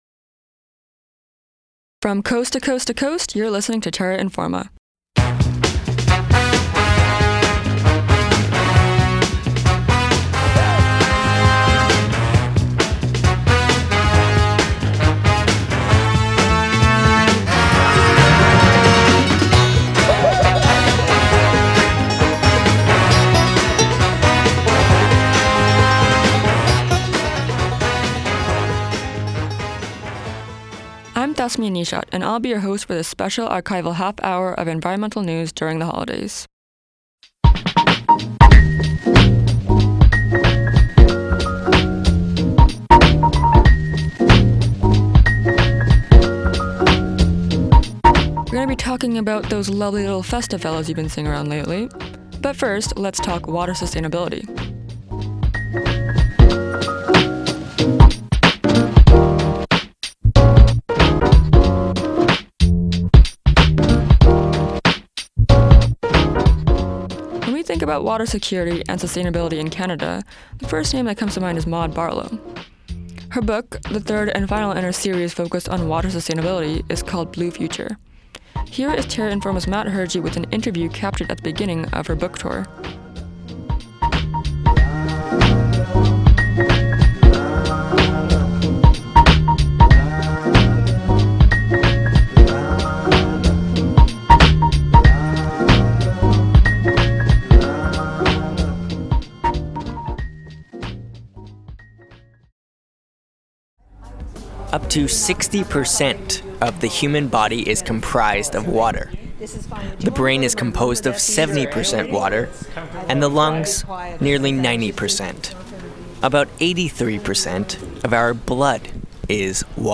Interview with Maude Barlow, and a showdown between artificial and real Christmas trees